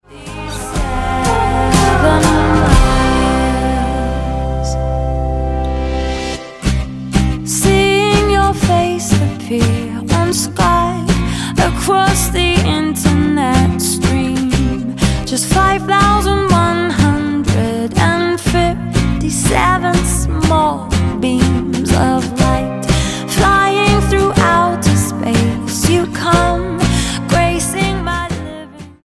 Pop Album
Style: Pop